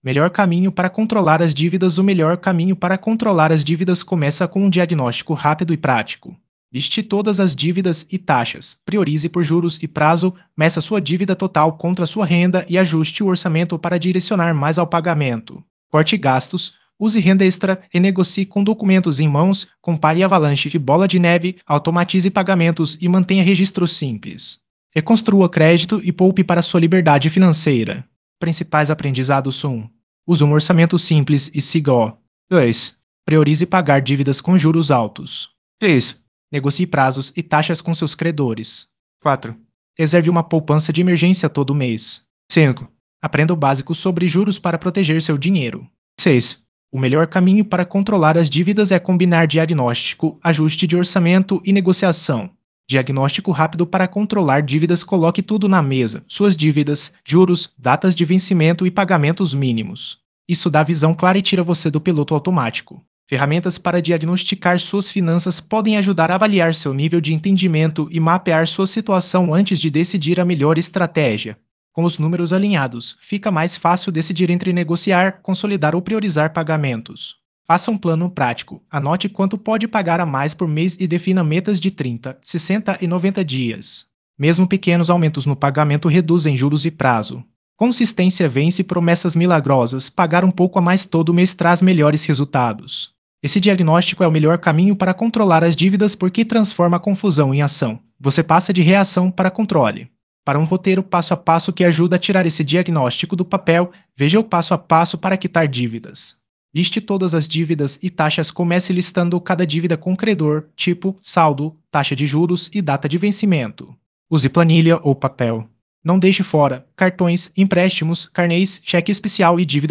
Ouça O Artigo via Áudio (Acesso Simples & Rápido)